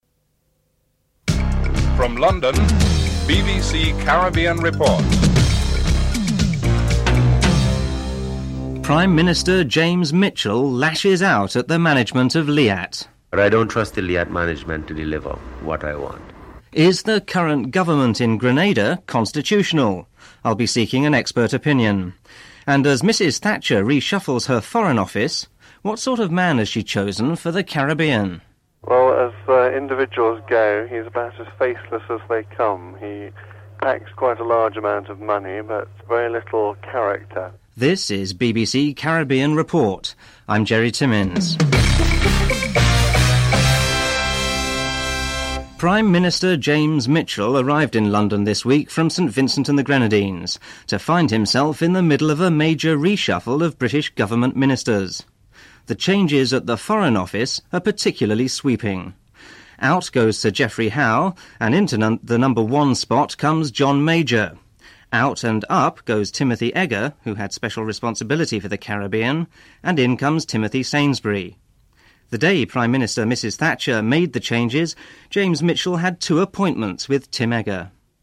1. Headlines (00:51-01:29)
5. Financial News (09:41-10:03)